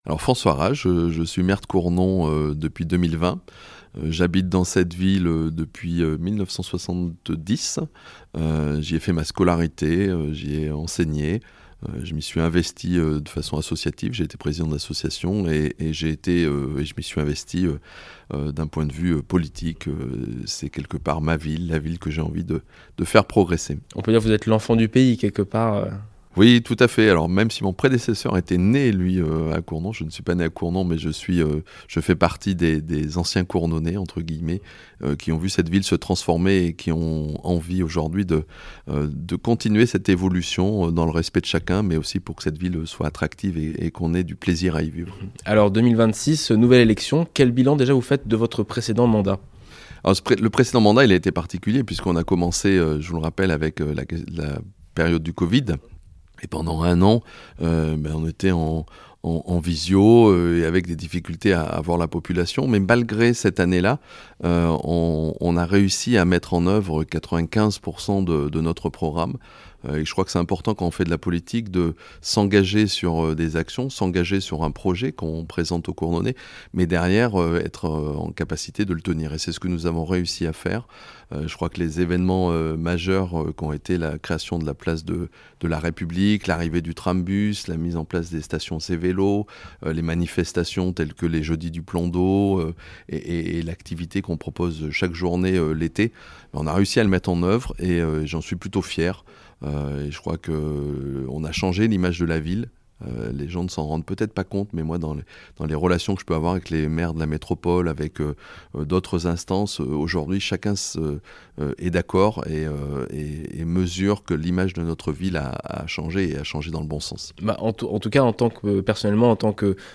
Nous nous sommes entretenu avec les trois candidats qui souhaitent briguer la mairie Cournonnaise jusqu'en 2033.
François Rage, maire sortant et tête de la liste "Vivons Cournon" (Union de la gauche), nous présente ses propositions et sa vision de la ville alors que le prochain mandat s'ouvre sur de nombreux défis.